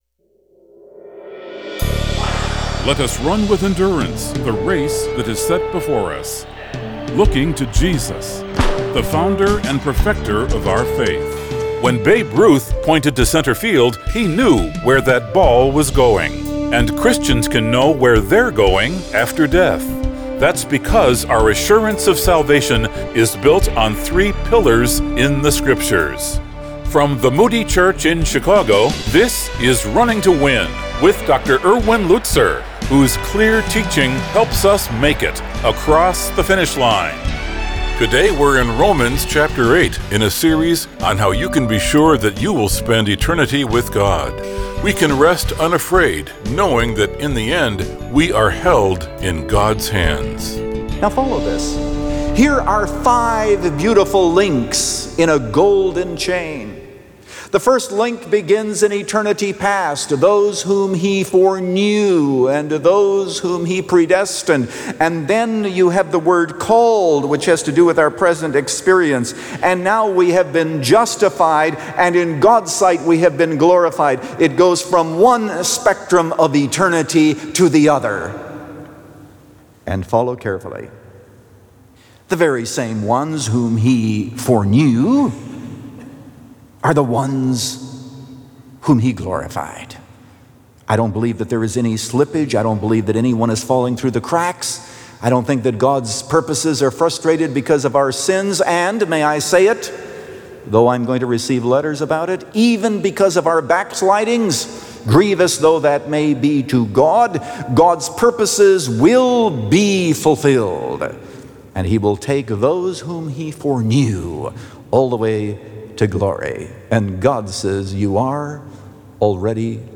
Held In God’s Hands – Part 2 of 3 | Radio Programs | Running to Win - 15 Minutes | Moody Church Media